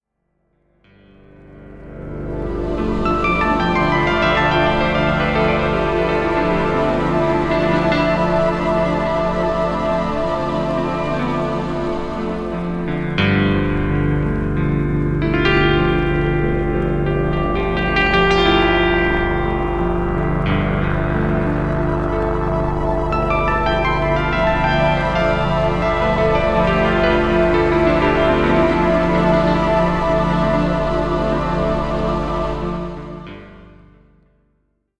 It is a keyboard connected to 19 players of 8-track cartridges (“8-Track”, used in the 70s/80s mainly in the USA). These cartridges have looped tapes and do not need to be rewound.
The sounds marketed were as follows: violins, string ensemble, cello, organ, male choir, brass, wind ensemble, transverse flute, recorder.